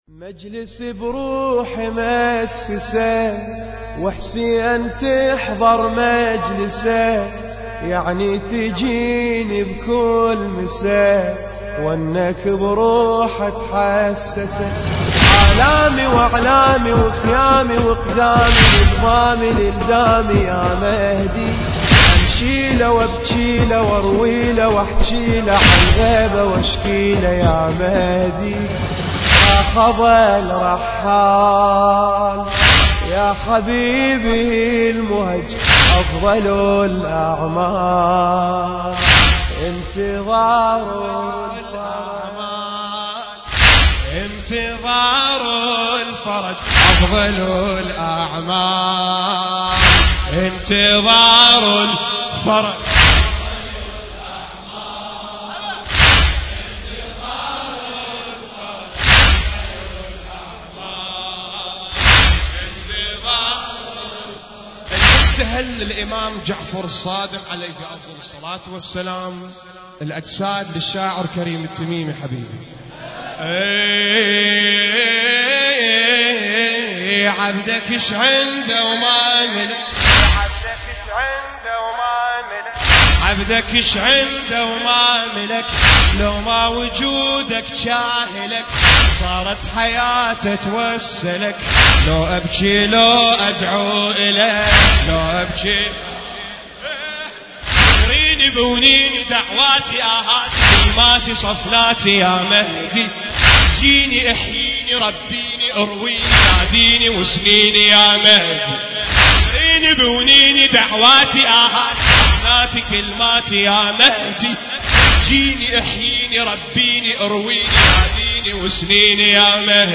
المكان: موكب الإمام المهدي عليه السلام/ بغداد